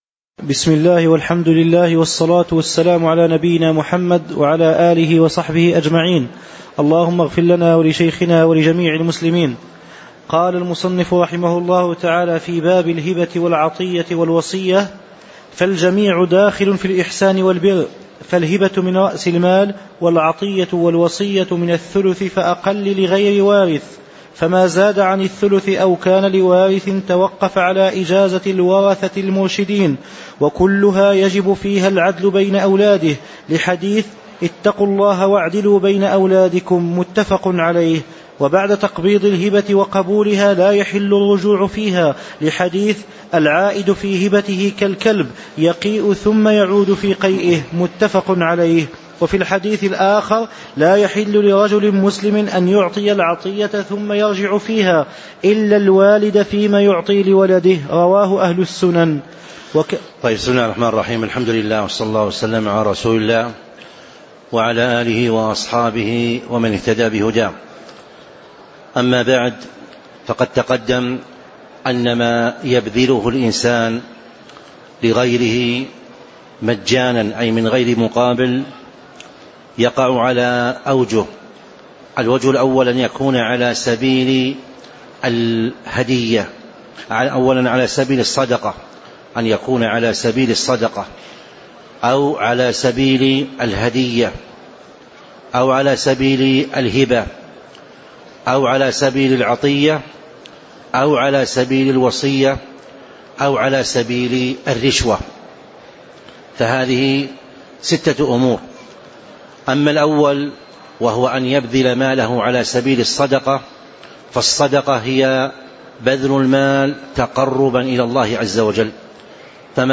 تاريخ النشر ٢٦ ربيع الثاني ١٤٤٦ هـ المكان: المسجد النبوي الشيخ